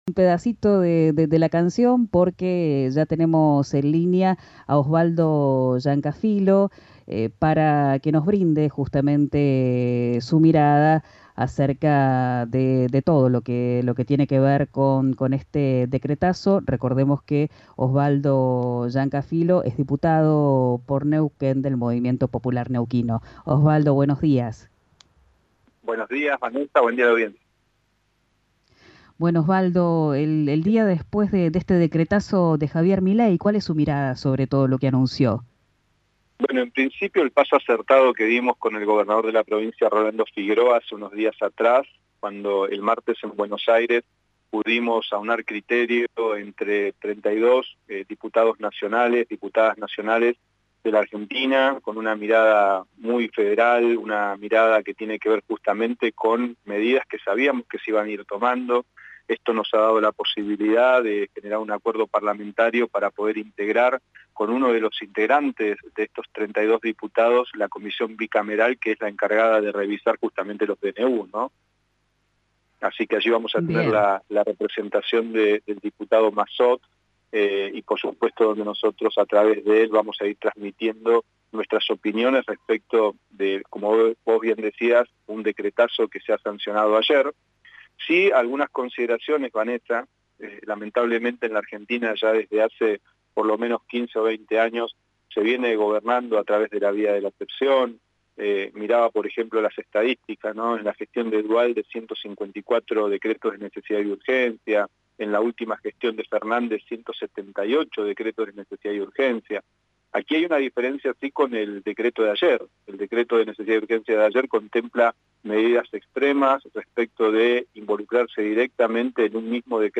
El diputado nacional del MPN, Osvaldo Llancafilo, habló en RIO NEGRO RADIO sobre el Decreto de Necesidad y Urgencia (DNU) anunciado por el presidente Javier Milei, que deroga múltiples leyes y normativas e impacta directamente en la totalidad de los aspectos de la vida cotidiana de los argentinos.